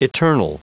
Prononciation du mot eternal en anglais (fichier audio)
Prononciation du mot : eternal